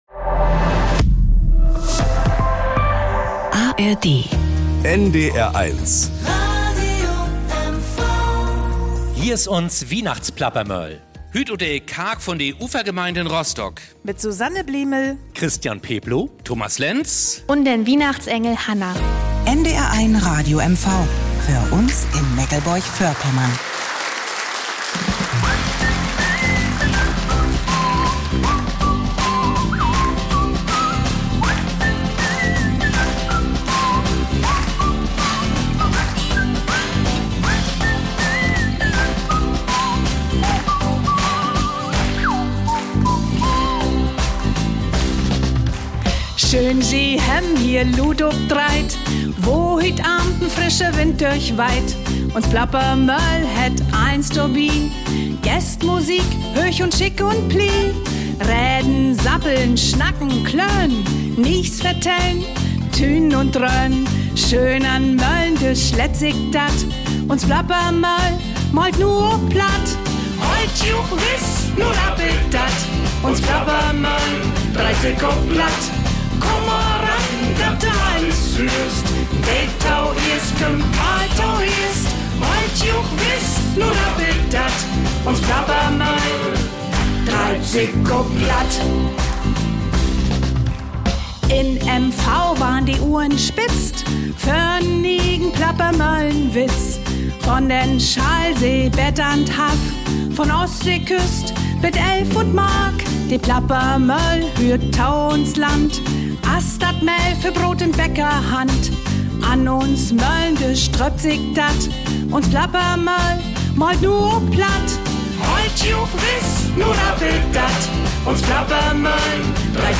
Viele fleißige Hände haben im Hintergrund diese Plappermoehl vorbereitet, haben Kuchen und Plätzchen gebacken, Kaffee gekocht und aus dem Kirchenraum der Ufergemeinde einen perfekten Ort für das Publikum, die Plappermoeller, ihre Gäste und die Musiker gemacht.
Und spätestens, wenn in dieser Sendung alle gemeinsam singen, leuchtet hell ein Licht aus Rostock in die Welt.